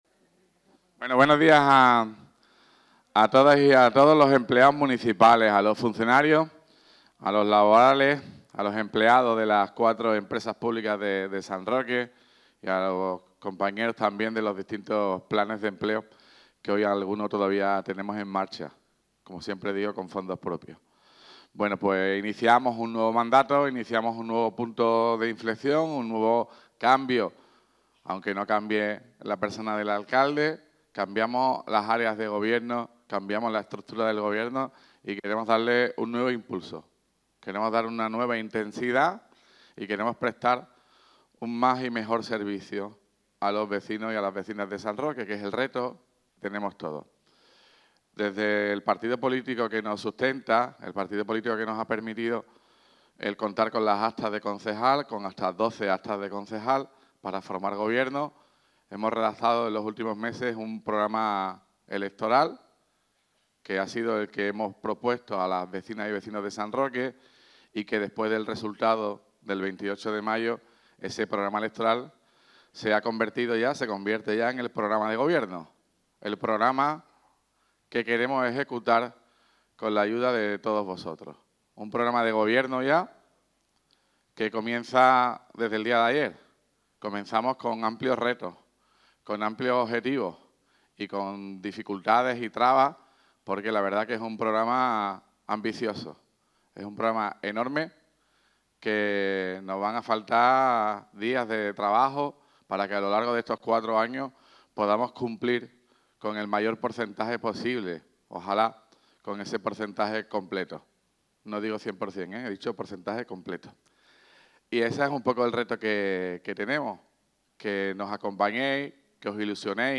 En el encuentro en el teatro, que agrupó a funcionarios, laborales, personal de las empresas municipales y trabajadores de los planes de empleo, Ruiz Boix estuvo flanqueado por los once ediles que le acompañan en el nuevo gobierno.
TOTAL ALCALDE PRESENTACIÓN DELEGACIONES.mp3